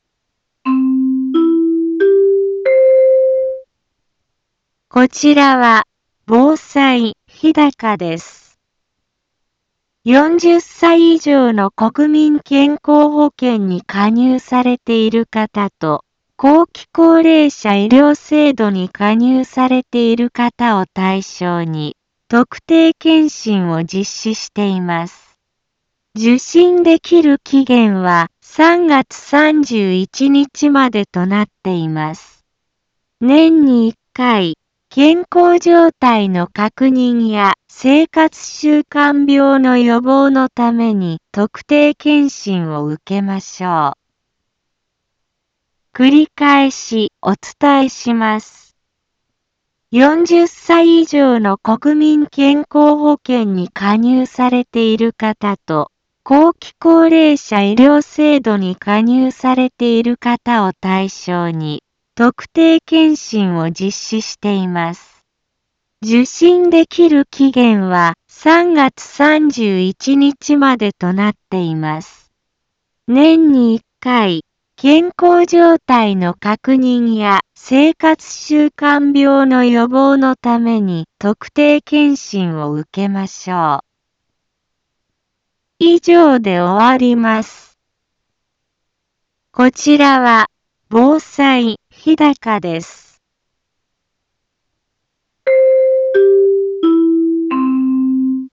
一般放送情報
Back Home 一般放送情報 音声放送 再生 一般放送情報 登録日時：2025-02-17 15:03:30 タイトル：特定検診のお知らせ インフォメーション： こちらは、防災日高です。 40歳以上の国民健康保険に加入されている方と後期高齢者医療制度に加入されている方を対象に特定検診を実施しています。